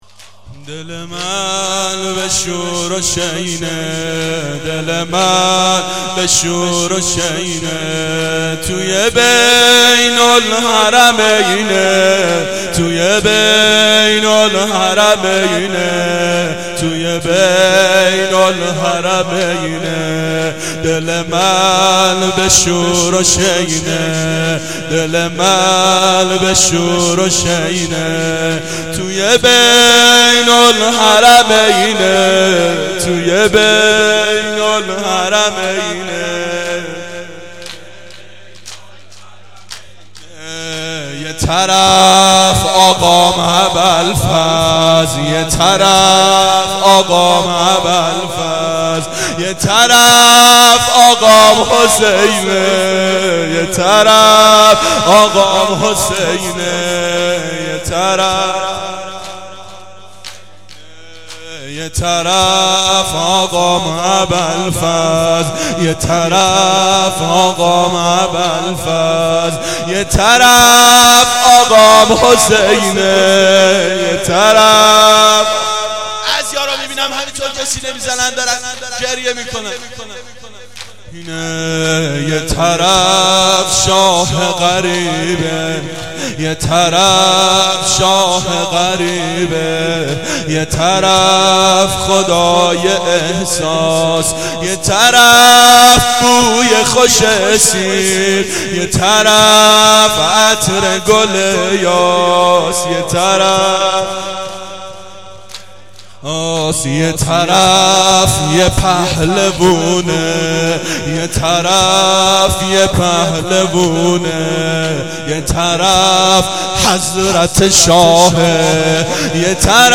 شب اول محرم 89 گلزار شهدای شهر اژیه